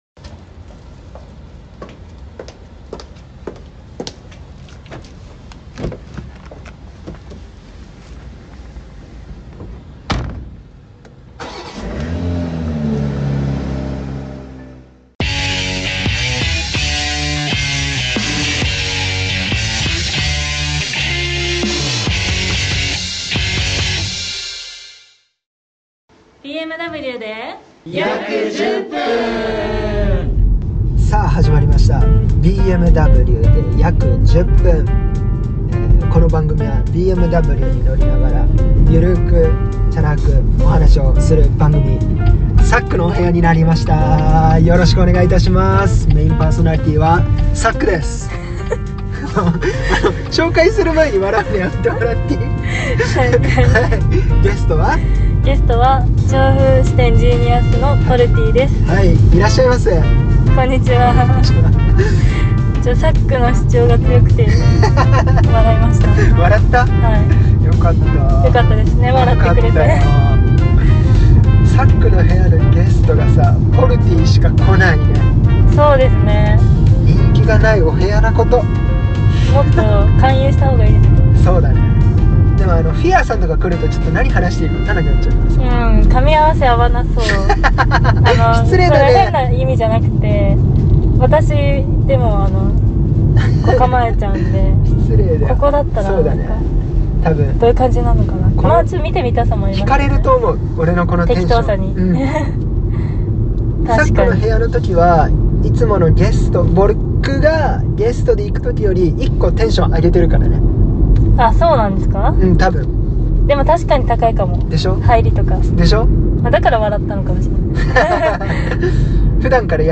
テーマは２０２１年を振り返るだそうです。２０２２年も半年たとうとしている今 もはや引き返せないほどのゆるトーク振りです。